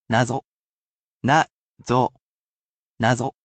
nazo